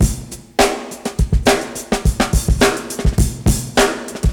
• 104 Bpm Drum Loop Sample G Key.wav
Free drum groove - kick tuned to the G note. Loudest frequency: 1665Hz
104-bpm-drum-loop-sample-g-key-fDi.wav